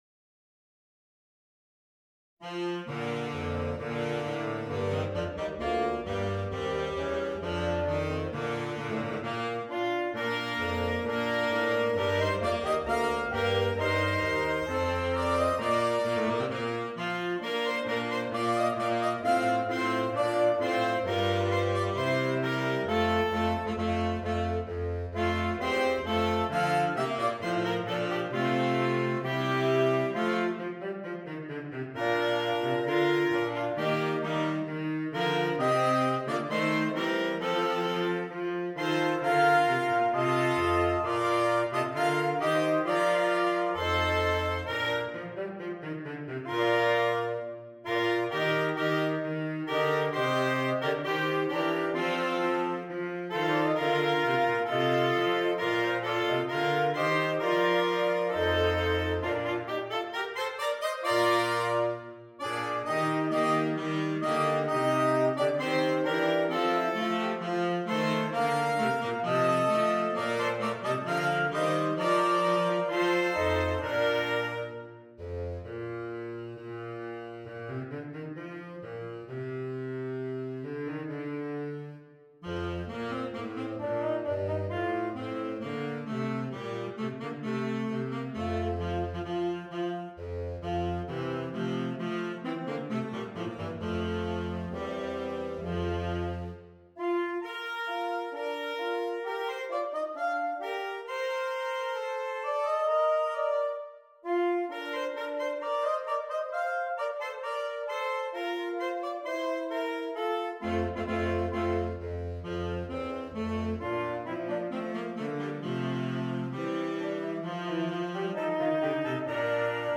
Saxophone Quartet (AATB)
Traditional
saxophone quartet